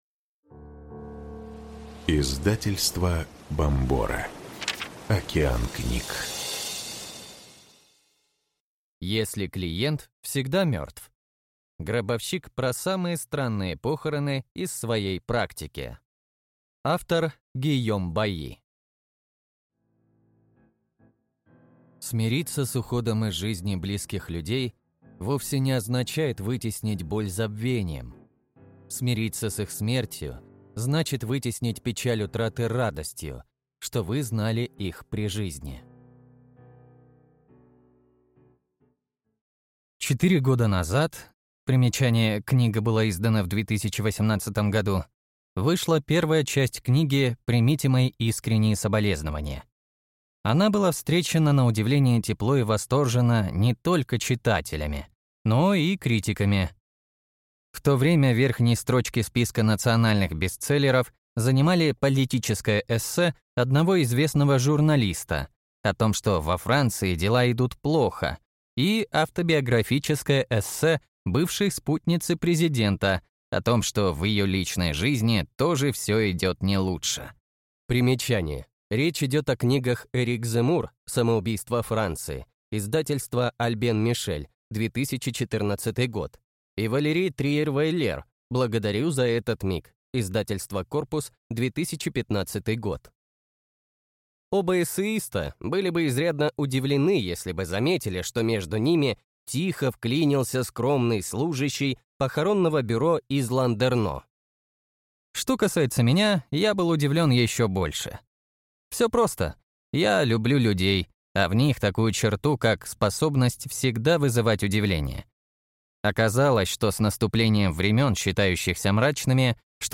Аудиокнига Если клиент всегда мертв. Гробовщик про самые странные похороны из своей практики | Библиотека аудиокниг